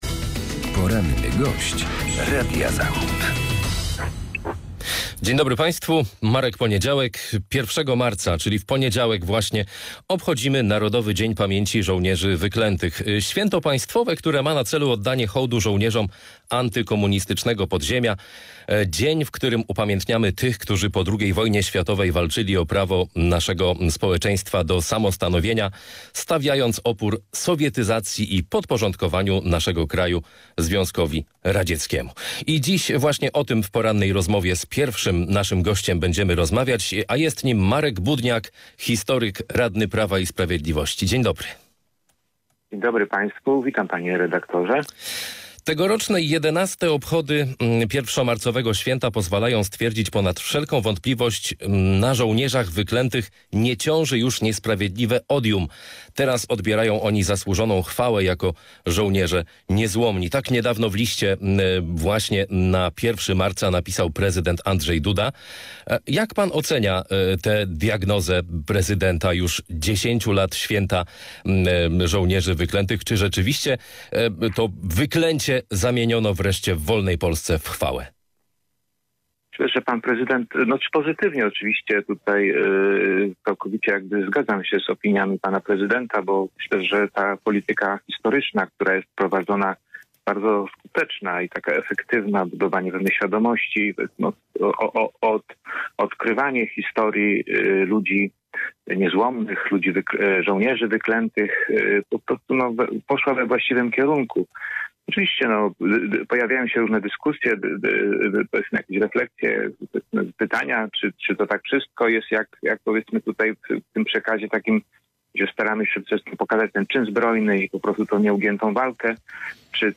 Z historykiem, radnym PiS rozmawia